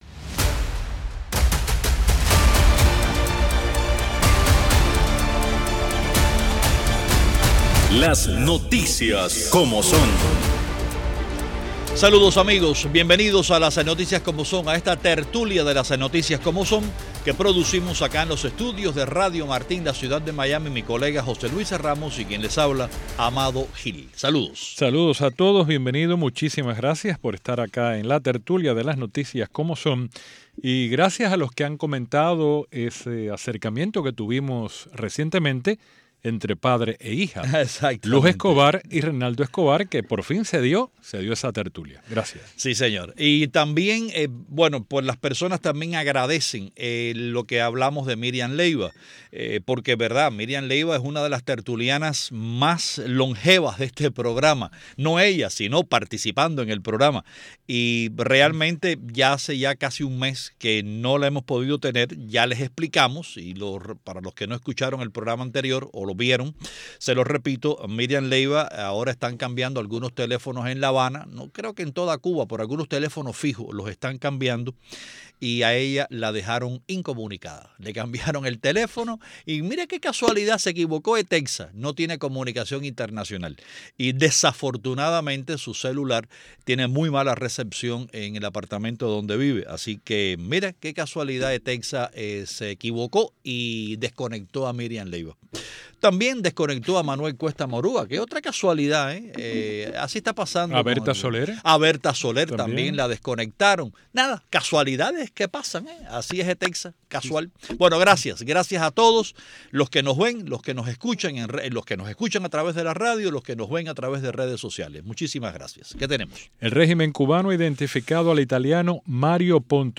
en tertulia